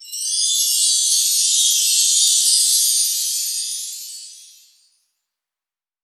Dilla Chime 03.wav